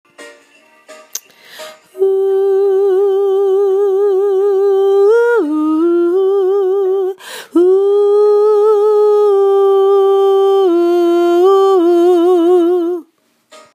download OEH-tjes